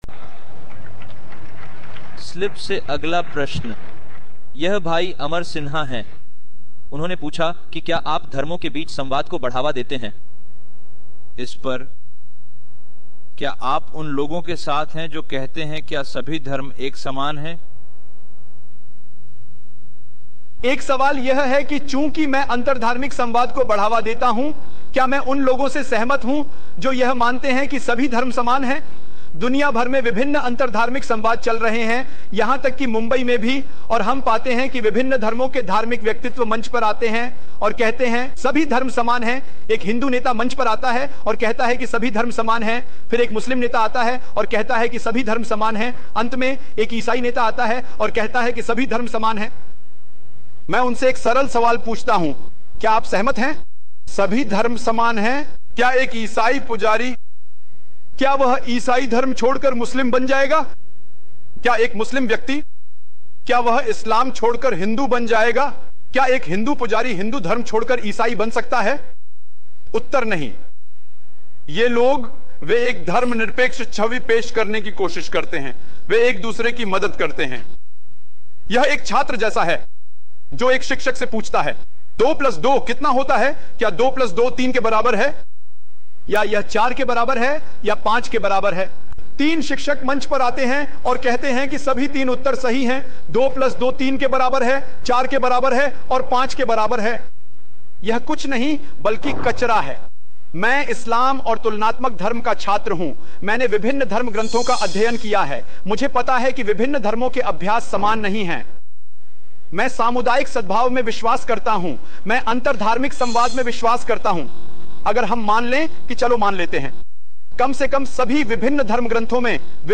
विवरण: इस वीडियो में डॉ. ज़ाकिर नाइक समझाते हैं कि सभी धर्मों को एक जैसा कहना क्यों उचित नहीं है।